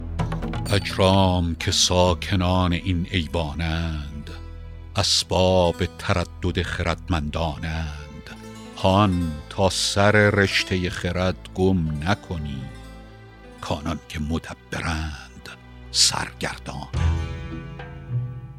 رباعی ۹ به خوانش فریدون فرح‌اندوز